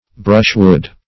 Brushwood \Brush"wood\, n.